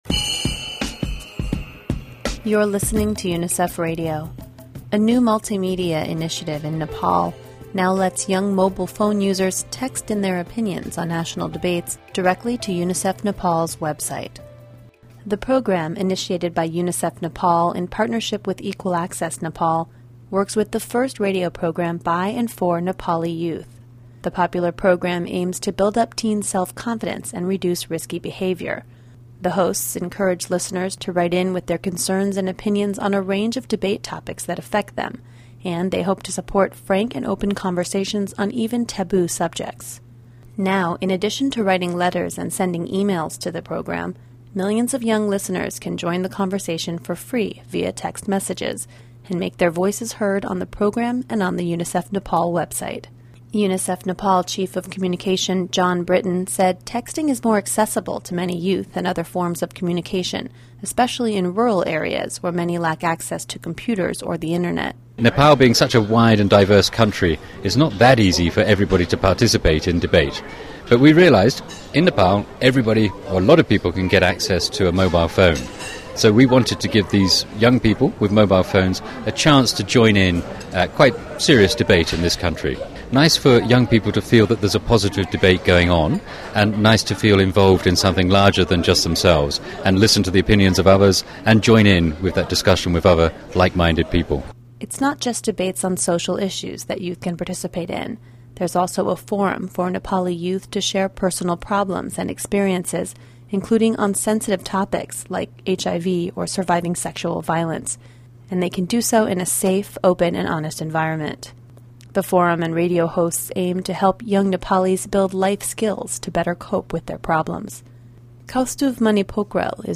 PROJECT ACTIVITIES: The young hosts’ frank youth-to-youth on-air discussions about the realities and responsibilities of adolescence helped young people to rise above daily conflicts, misguided expectations, and peer pressure.